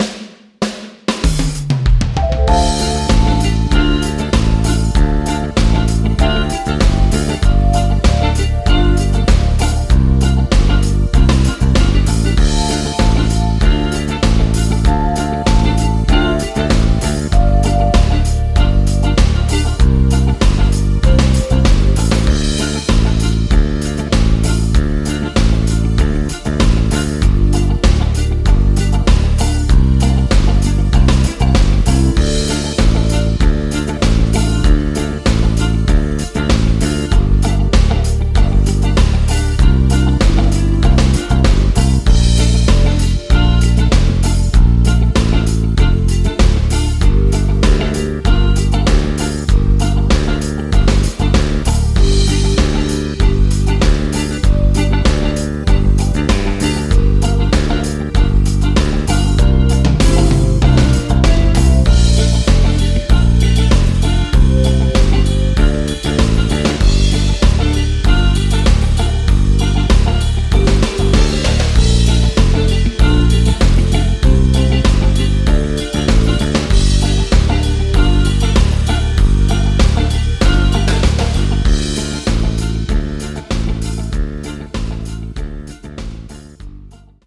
Playback, Karaoke, Instrumental